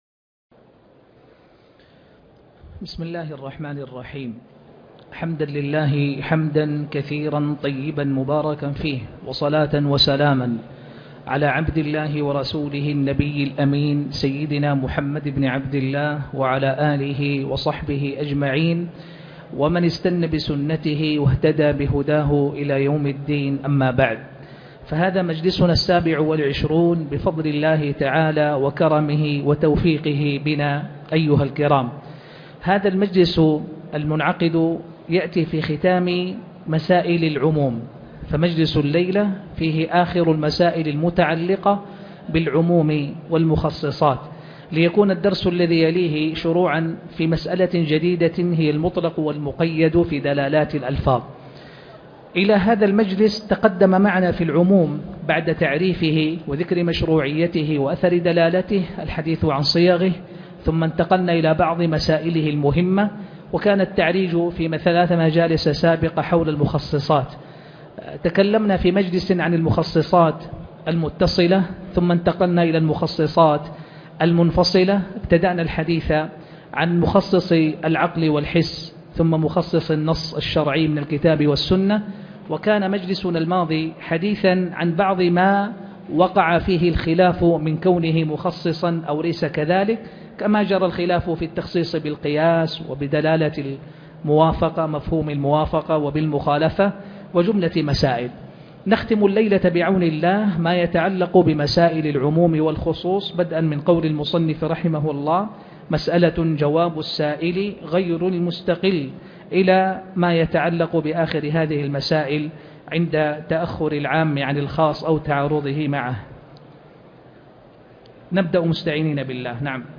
شرح جمع الجوامع الدرس 26